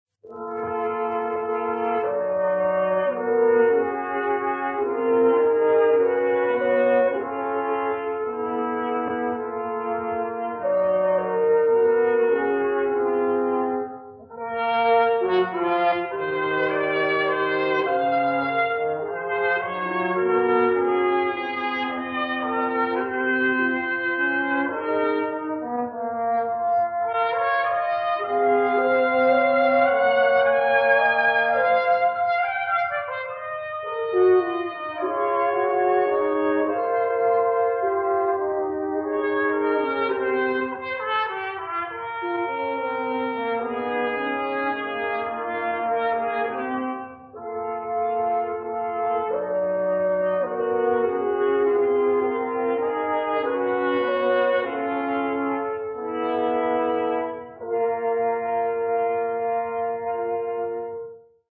Trompete, Horn, Posaune
Ruhig fließend  (1'01" - 1,0MB)